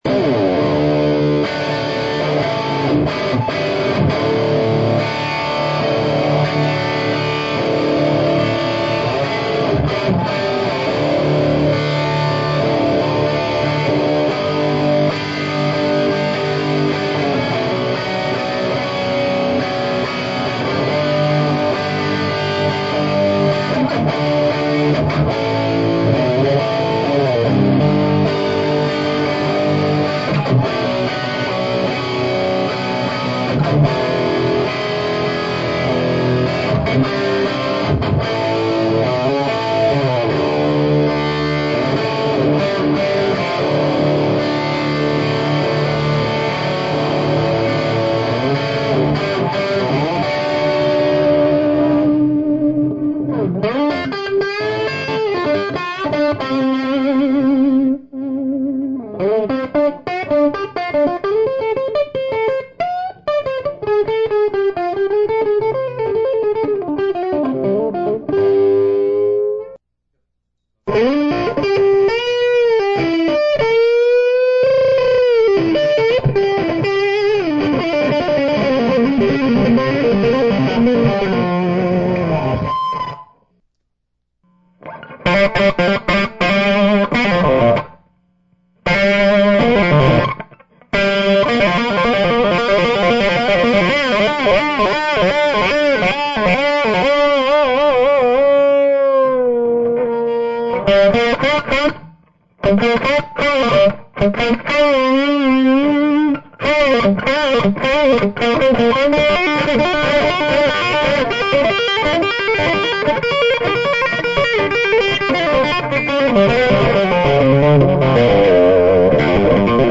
6V6 Express clone - Vol at 10:00